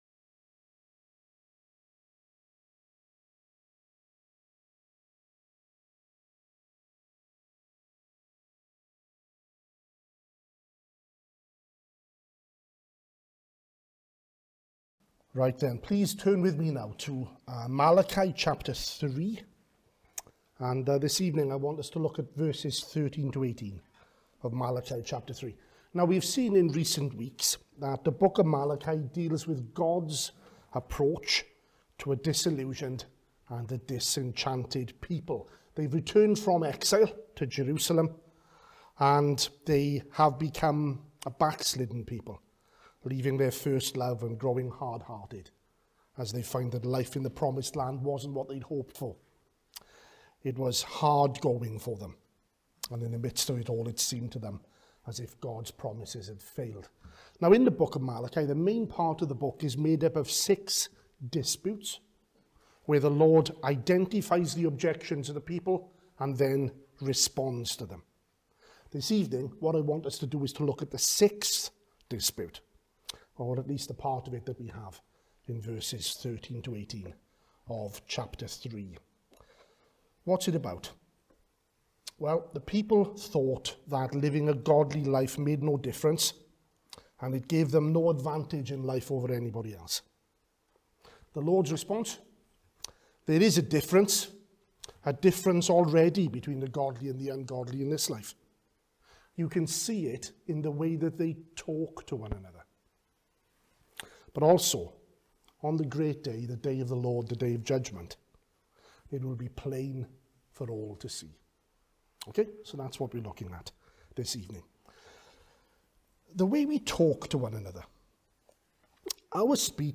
at the evening service